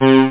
1 channel
303-analog.mp3